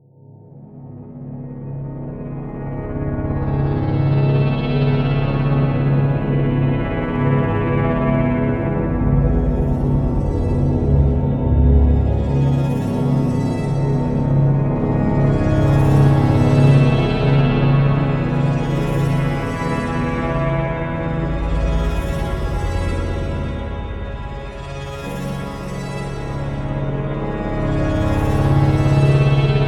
Tags: SOUND FX